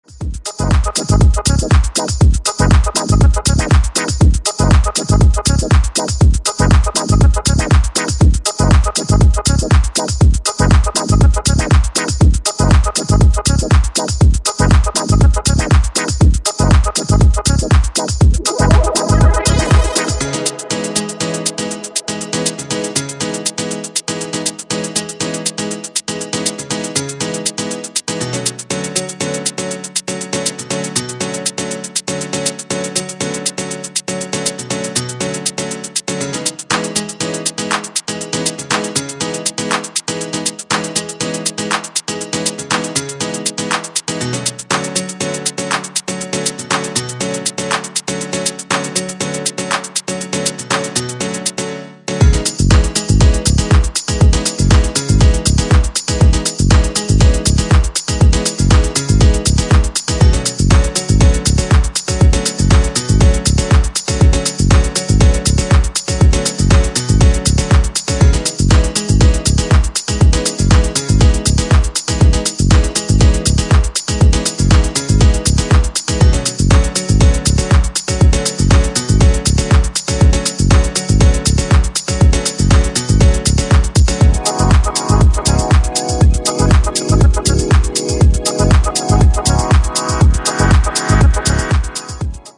ジャンル(スタイル) DEEP HOUSE / RE-EDIT